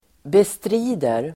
Uttal: [bestr'i:der]